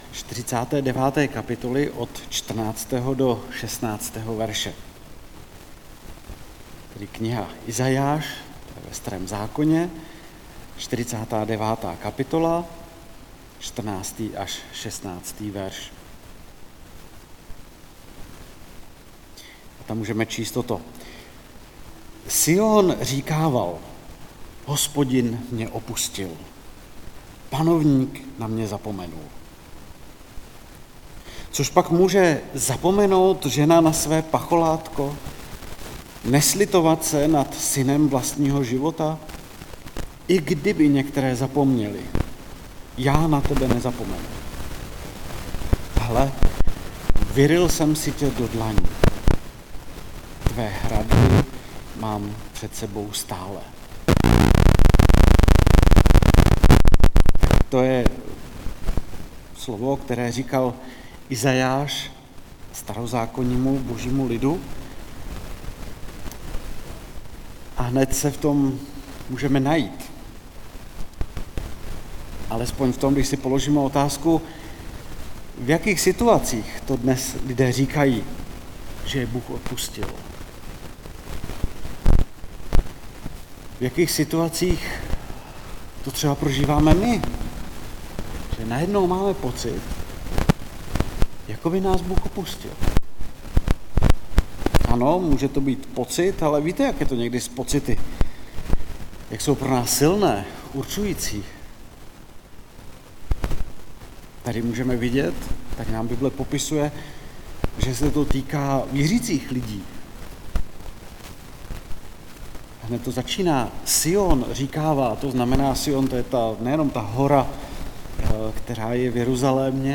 Nedělní bohoslužby